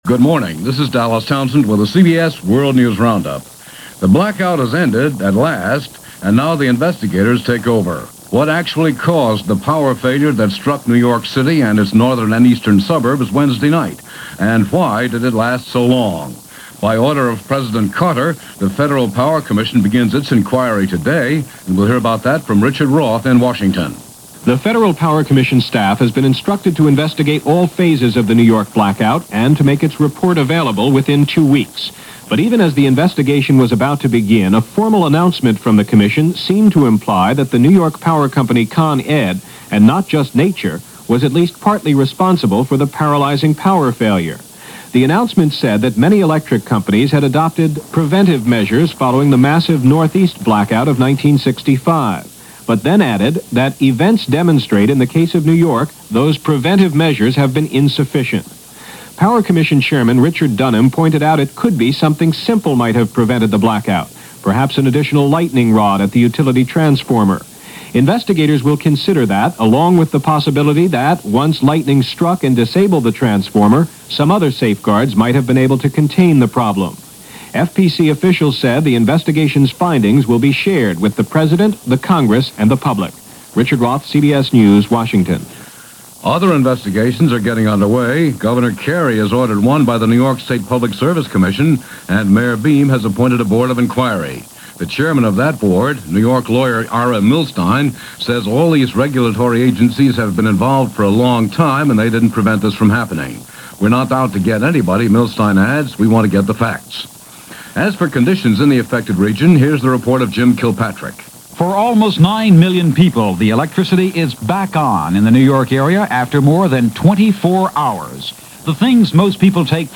New York Blackout: The Aftermath - Questions In North Korea - Begin Comes To America - July 15, 1977 - CBS World News Roundup